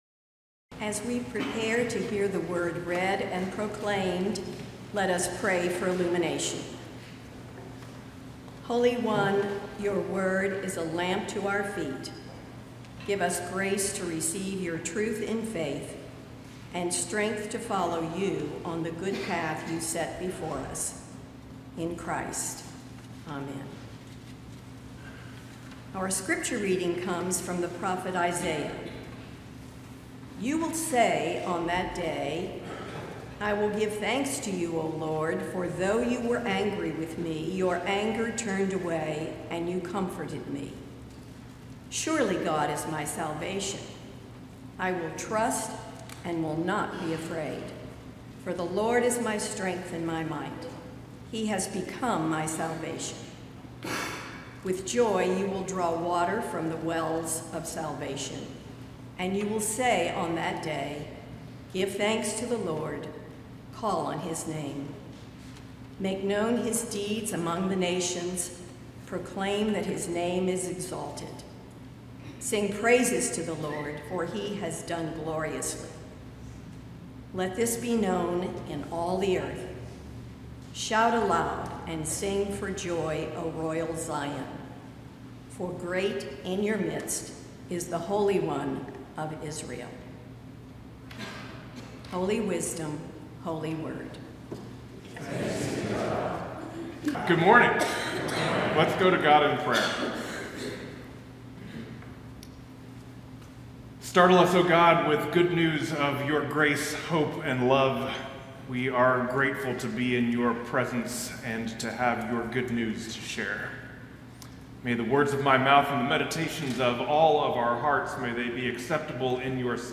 So I take the time, periodically to preach a sermon that may not be inspirational or motivational, but is simply about understanding the Bible a bit better.